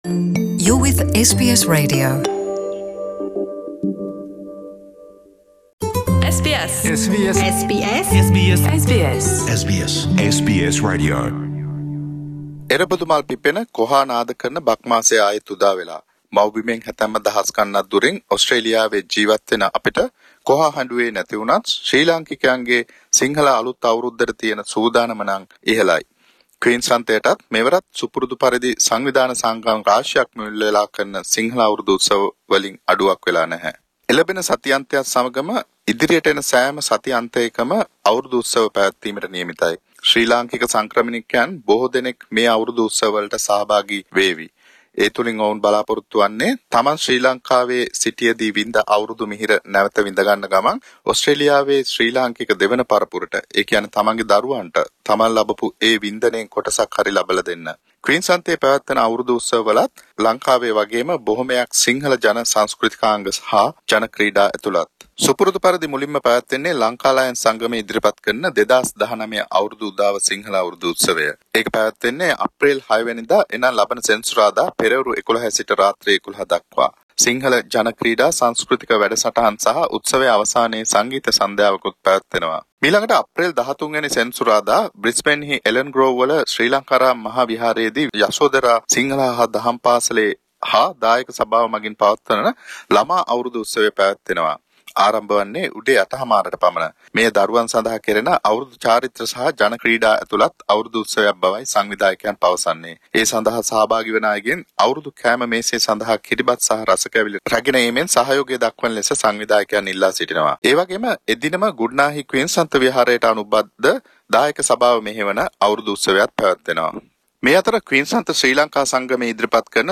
ඕස්ට්‍රේලියාවේ ක්වීන්ස්ලන්ත ප්‍රාන්තයේ දසත පැවැත්වෙන බක්මහ උළෙල පිළිබඳ SBS සිංහල ගුවන් විදුලියේ විශේෂ වාර්තාව